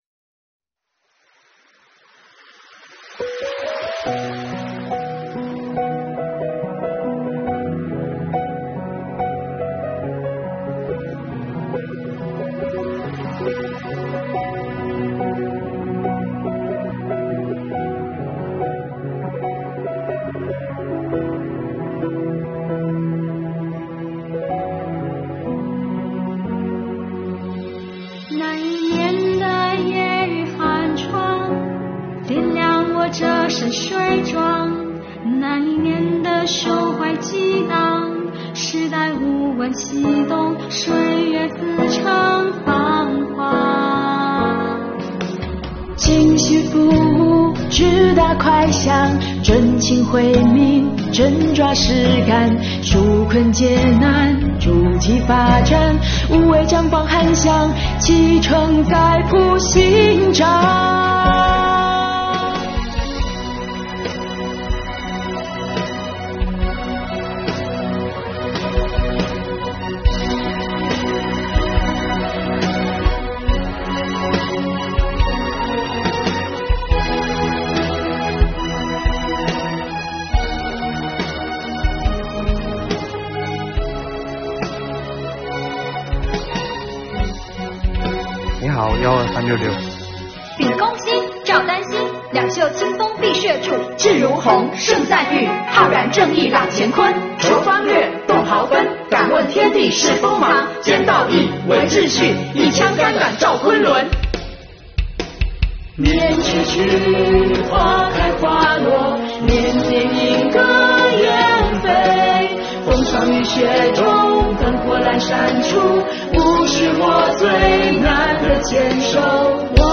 俯下身是人民的好儿女，昂起头是正义的稽查人。国家税务总局漳州市税务局青年干部用汗水点亮税收事业之光，用青春唱响留抵退税护航之歌。